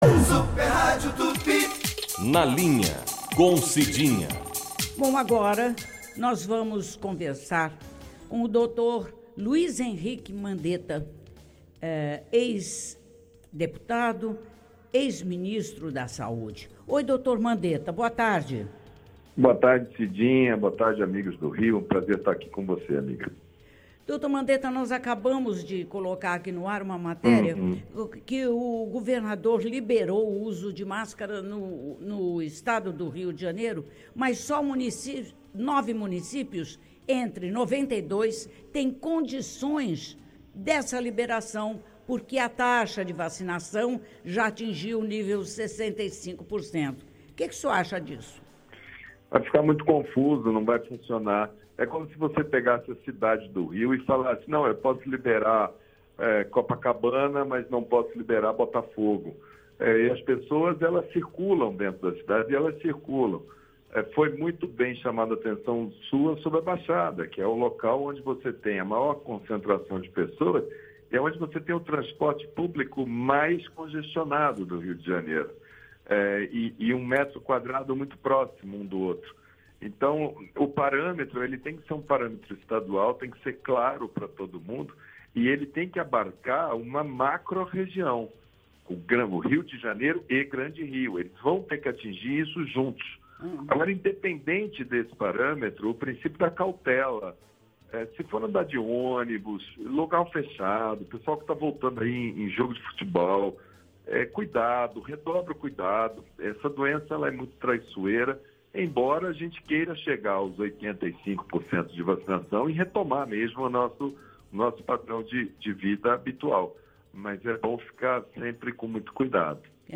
Ouça a entrevista completa abaixo:
O ex-ministro da Saúde Luiz Henrique Mandetta concedeu uma entrevista exclusiva, na tarde desta quinta-feira (28), ao programa “Cidinha Livre”, da Super Rádio Tupi. Na conversa com a comunicadora Cidinha Campos, o médico comentou sobre diversos assuntos, dentre eles a falta de liderança no combate à pandemia da Covid-19 e a decisão do estado do Rio de Janeiro em liberar a não utilização de máscaras de proteção em locais abertos.